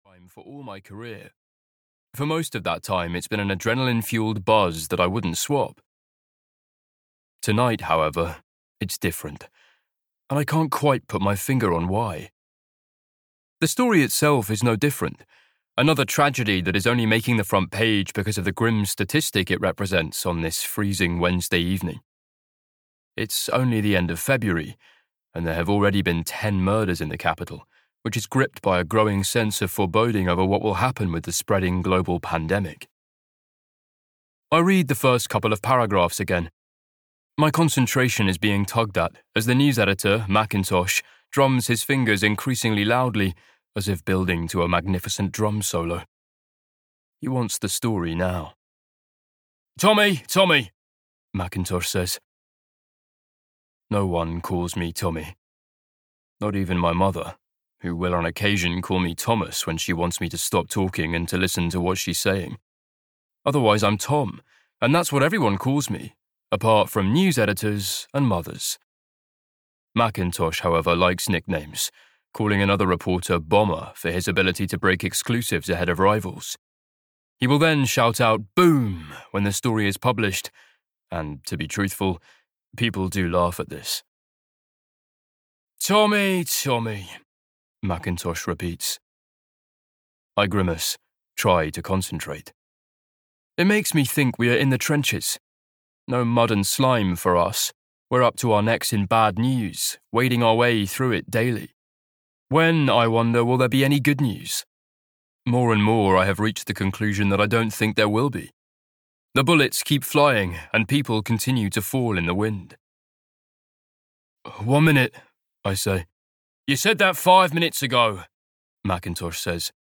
Blind Dates (EN) audiokniha
Ukázka z knihy